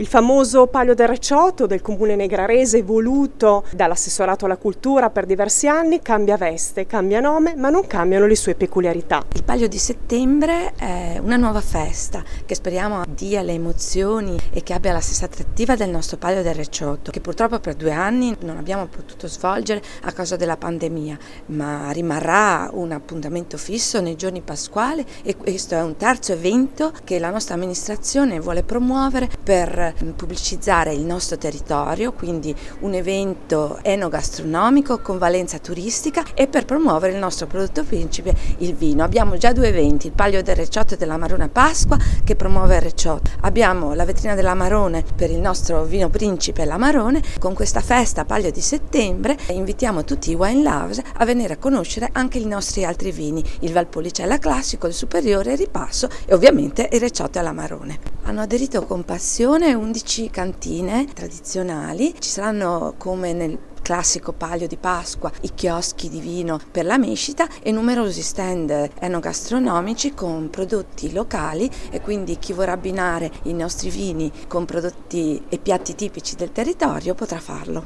Una nuova manifestazione per il comune di Negrar che si aggiunge alle altre due, sempre dedicate al prodotto principe della zona, ovvero il vino. L’assessore alla cultura Camilla Coeli: